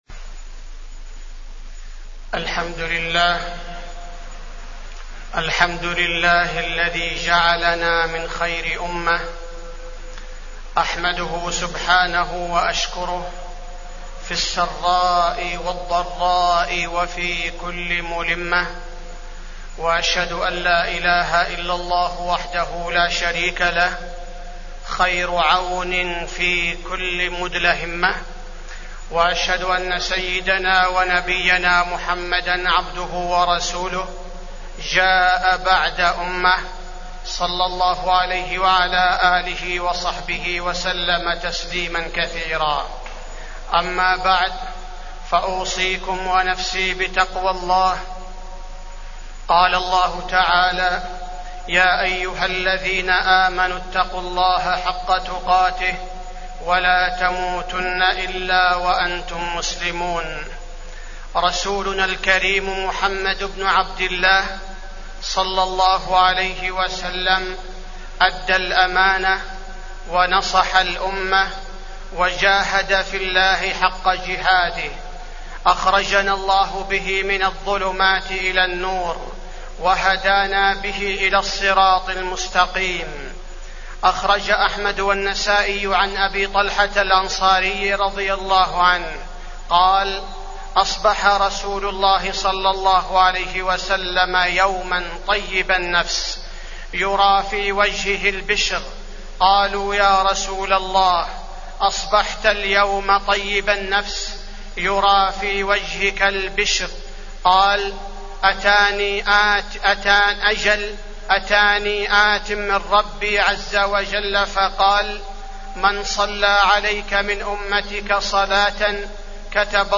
تاريخ النشر ١٥ رجب ١٤٢٩ هـ المكان: المسجد النبوي الشيخ: فضيلة الشيخ عبدالباري الثبيتي فضيلة الشيخ عبدالباري الثبيتي فضل الصلاة على النبي عليه الصلاة والسلام